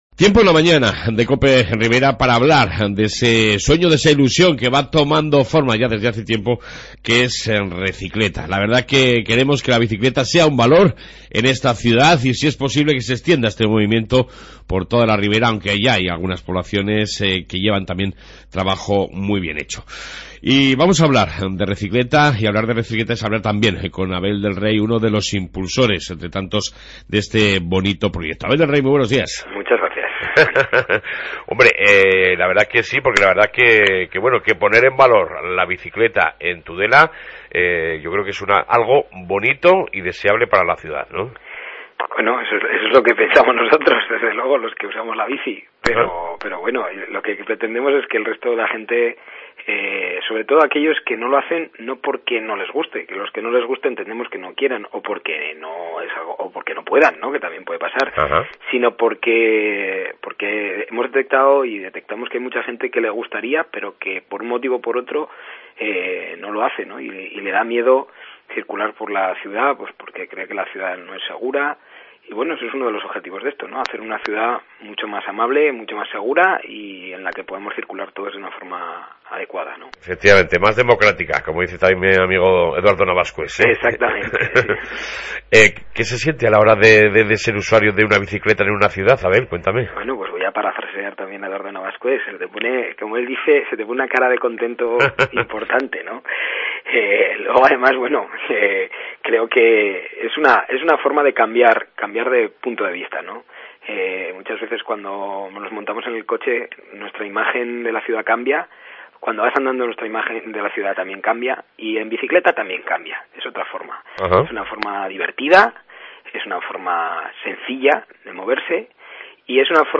AUDIO: Entrevista con Recicleta, Amimet y Club Ciclista Muskaria, sobre el nuevo proyecto de donación y reciclaje de bicicletas